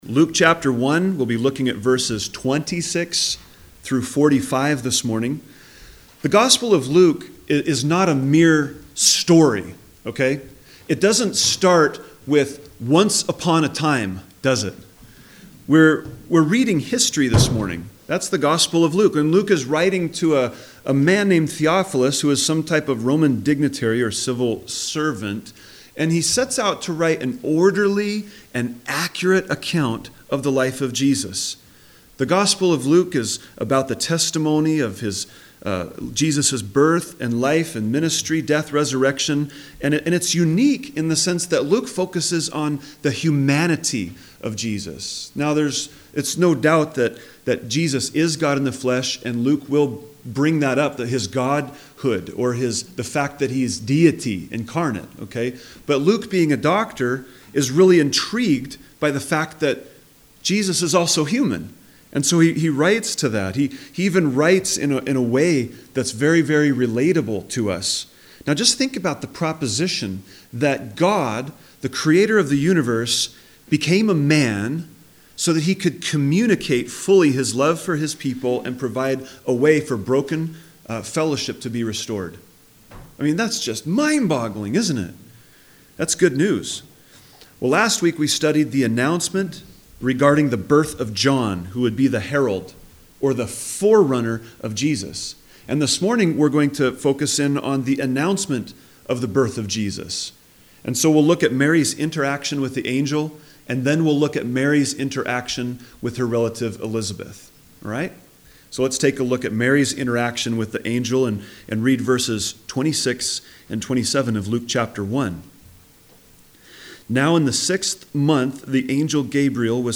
A message from the series "Gospel of Luke."